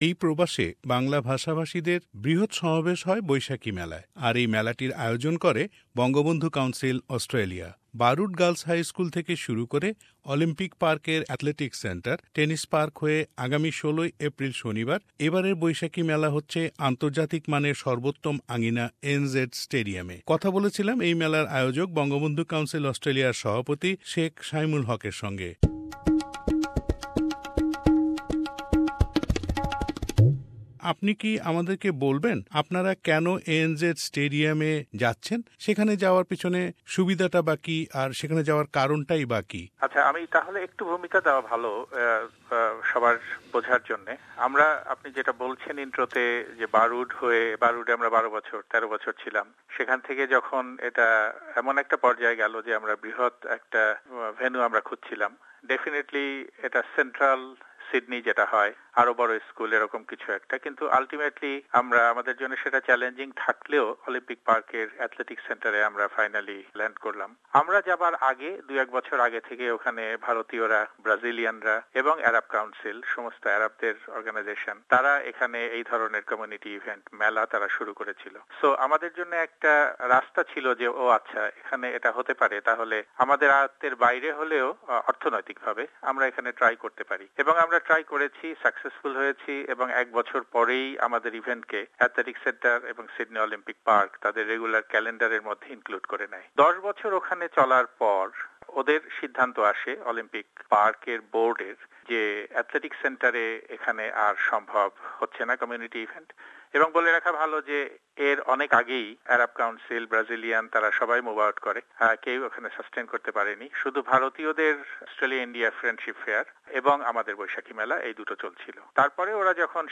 Over the two decades Boishakhi Mela became the biggest community event organized by Bangabandhu Council Australia. Interview